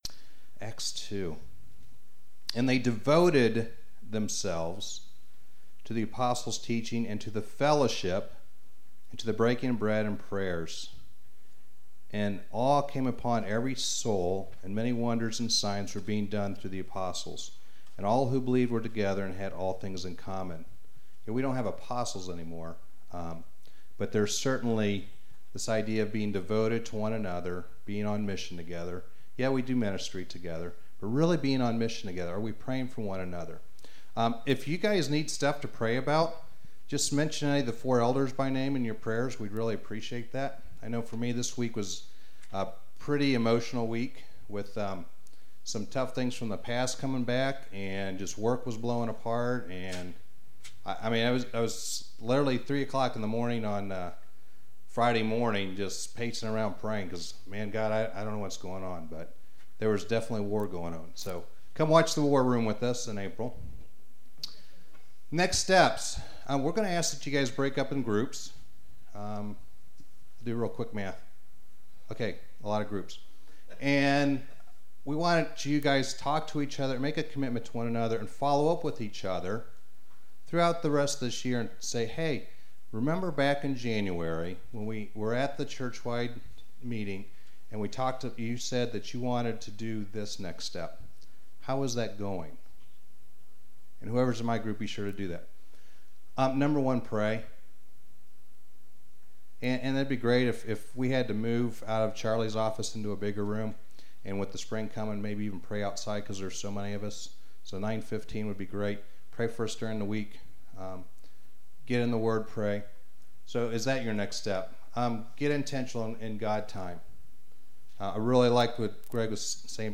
Sermons - Darby Creek Church - Galloway, OH